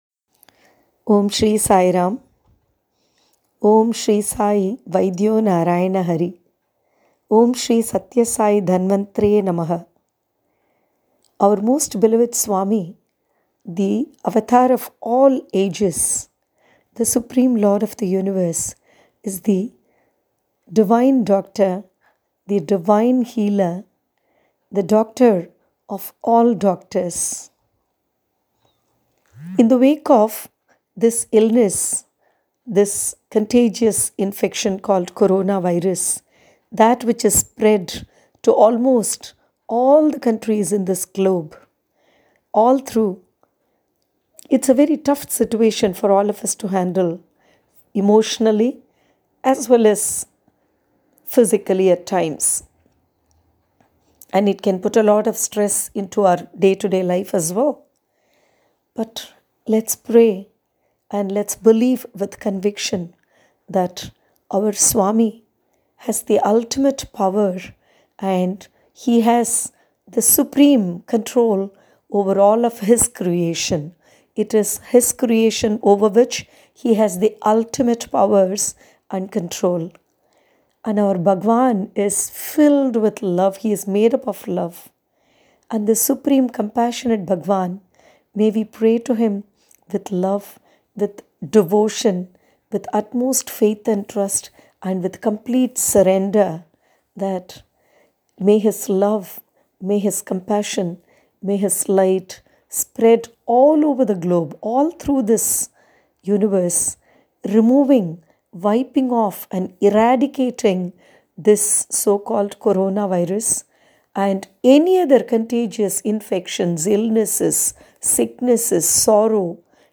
PassionatePrayer3.mp3